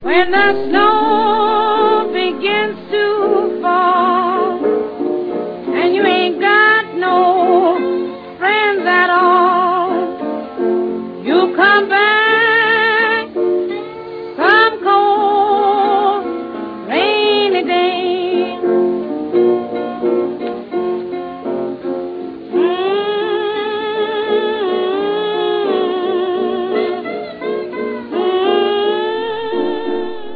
фортепиано
гитара